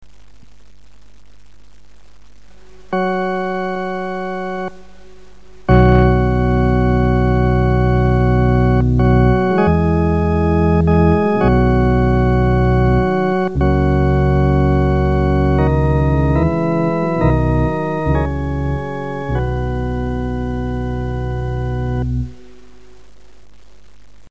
Masonic Music for Lodge and Chapter
Organ.